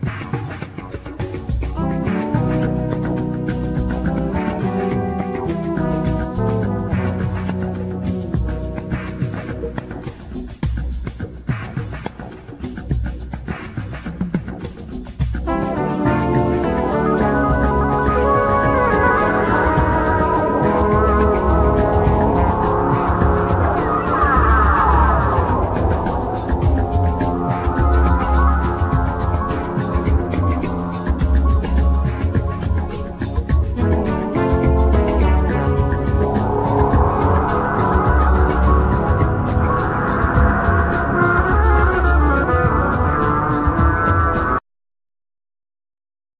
Drums
Trombone
Percussion
Tenor Sax,Clarinet
Bass
Trumpet